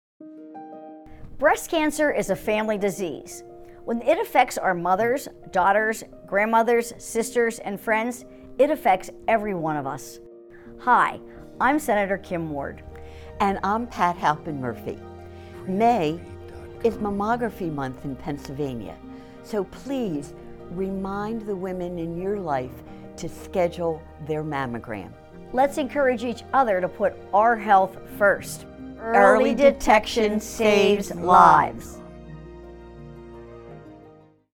Radio PSA
May-Mammography-Month-PSA-1.mp3